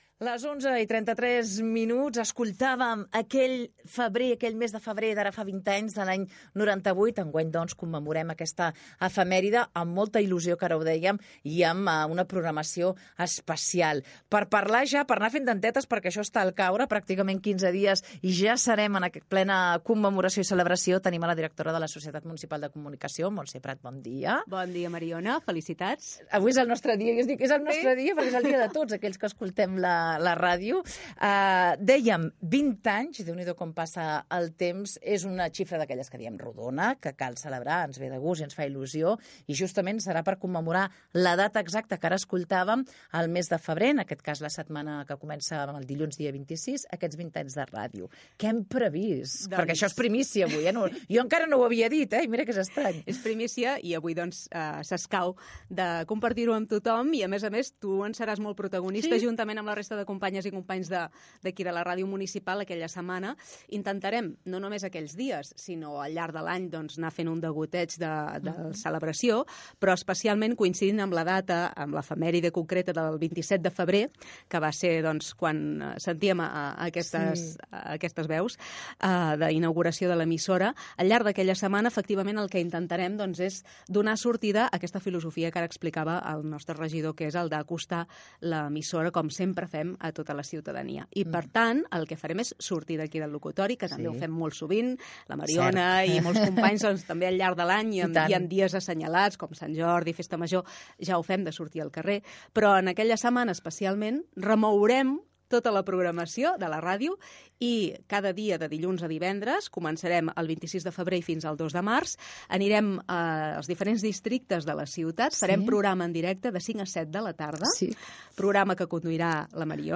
Entrevista
Recull d'enregistraments històrics de l'emissora Gènere radiofònic Info-entreteniment